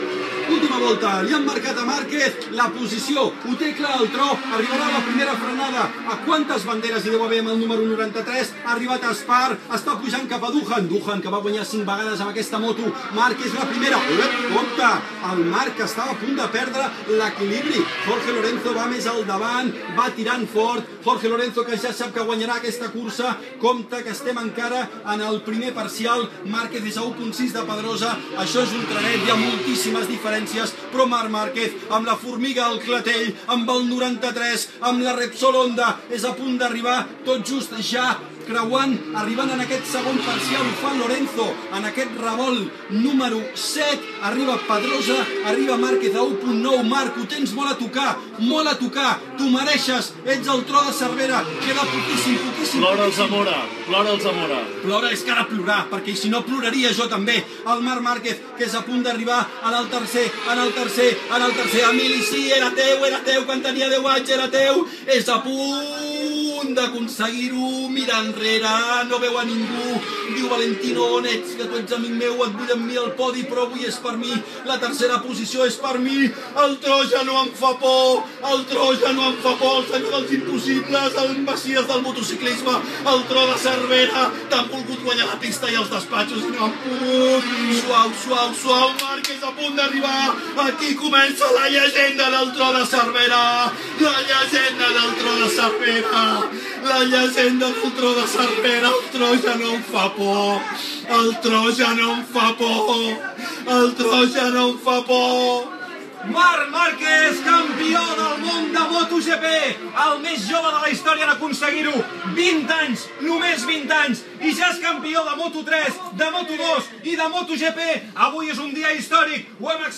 Retransmissió del final de la cursa de MotoGP, al circuit de Xest en què el pilot Marc Màrquez es va proclamar campió del món d'aquesta categoria per primera vegada
Esportiu